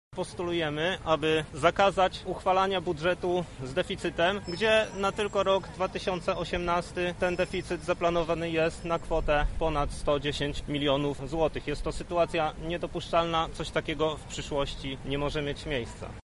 – mówi Jakub Kulesza.